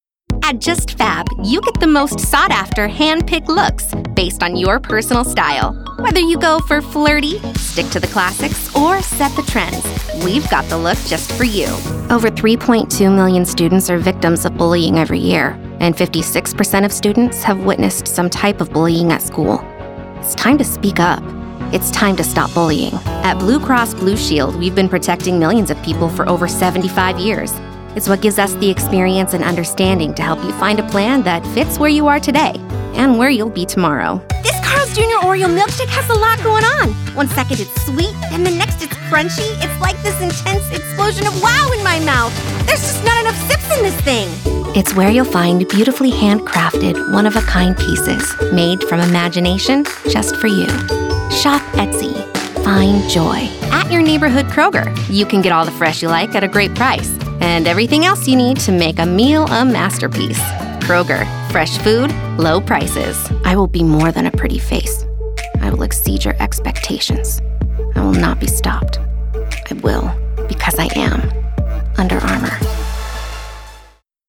Demo
Child, Teenager, Young Adult, Adult